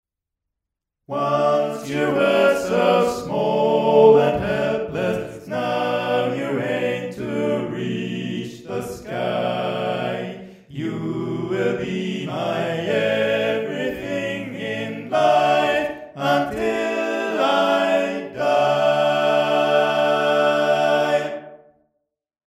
Key written in: D Major
Type: Barbershop